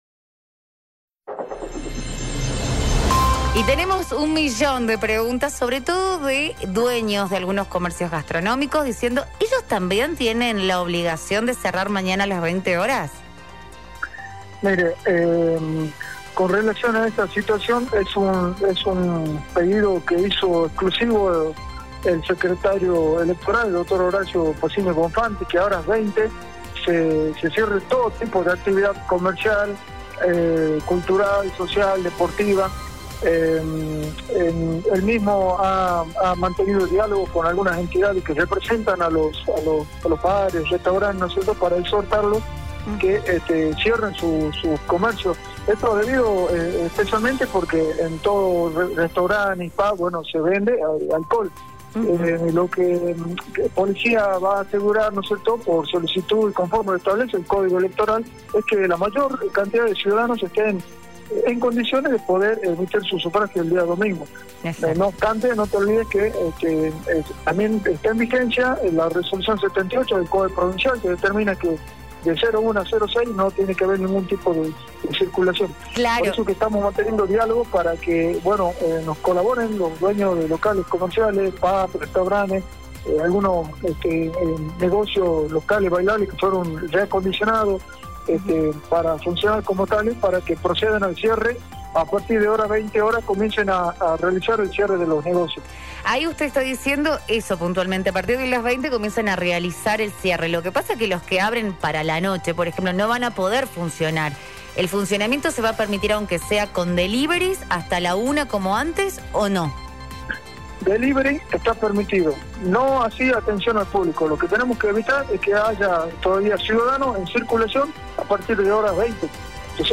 HERBAS MEJÍAS - JEFE DE POLICÍA.mp3